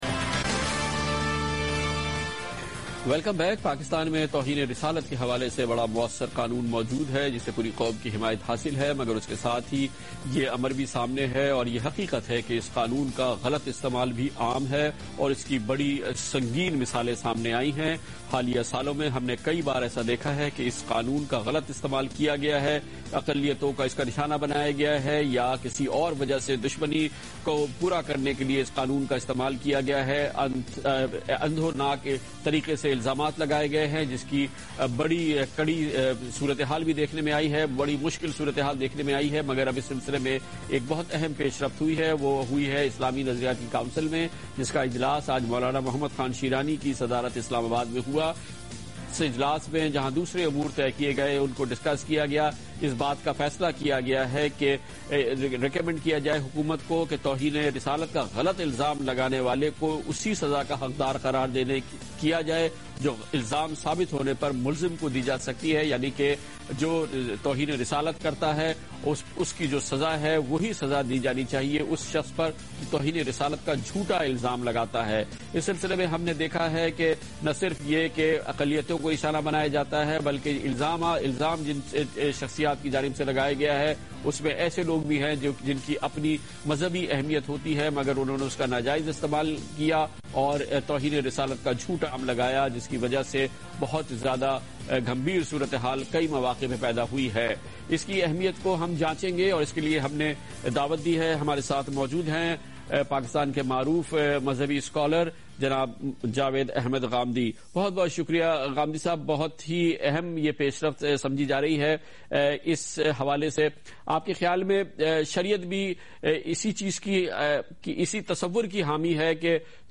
Category: TV Programs / Geo Tv /
جیو ٹی وی کے اس پروگرام میں جاوید احمد صاحب غامدی "توہین رسالت کے جھوٹے الزام کی سزا " کے متعلق آپی آراء کا اظہار کر رہے ہیں